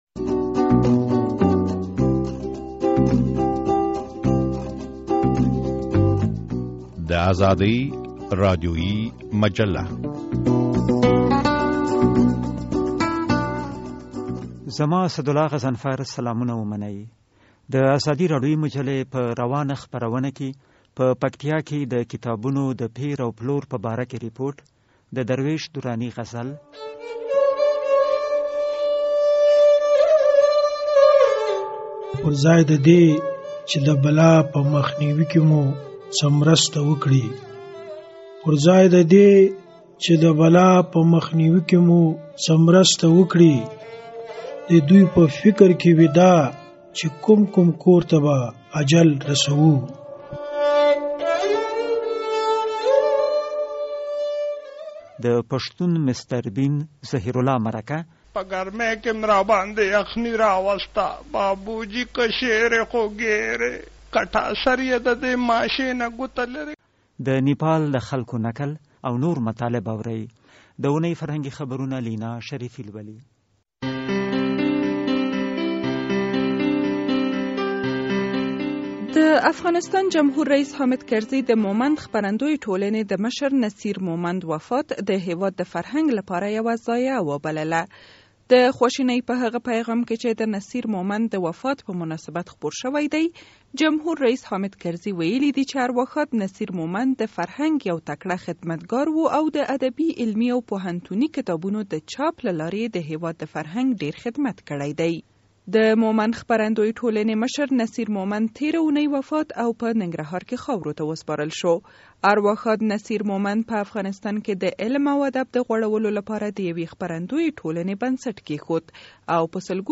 په پکتیا کې د کتاب د بازار د غوړېدو په باره کې یو رپورټ اورئ. له هغه چا سره مرکه اورئ چې د پښتو مسټر بین په نوم مشهور شوی دی.